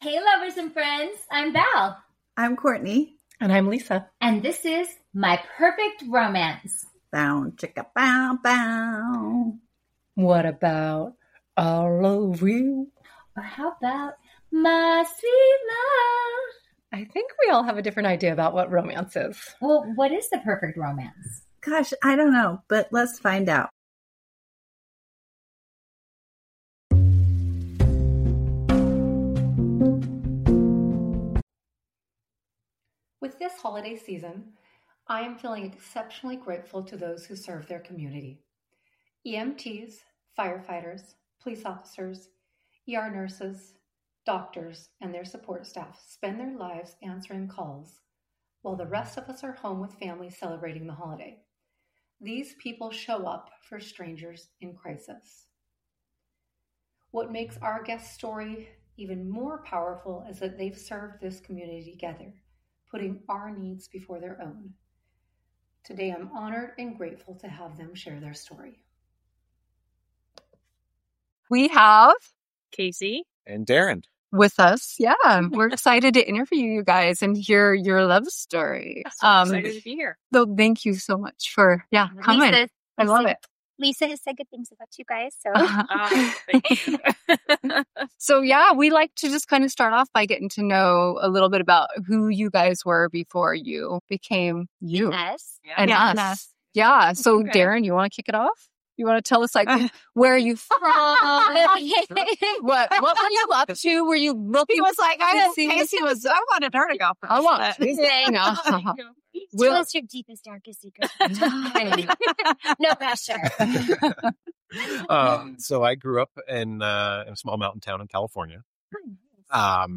1 Mistletoe Murders Podcaster Junket Interview- Sarah Drew and Peter Mooney (Hallmarkies Podcast) 1:09:01